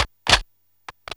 GunCock.wav